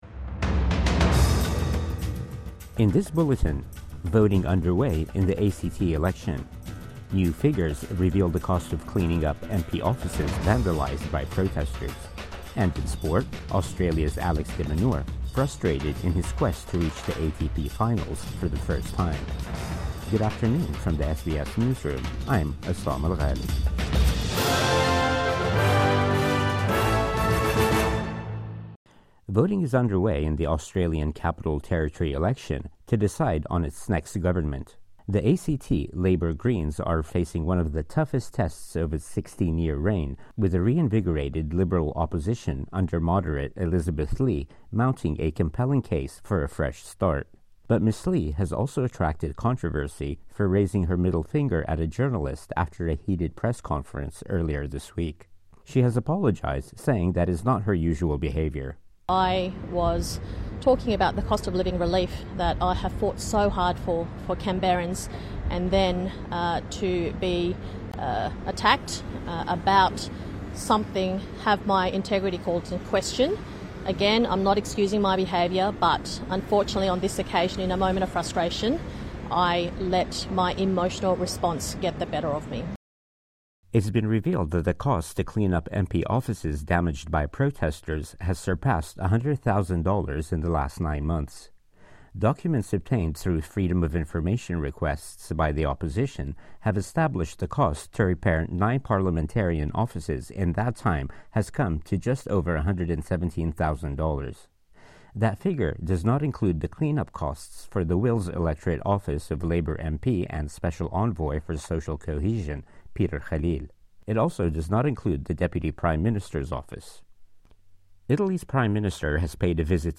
Midday News Bulletin 19 October 2024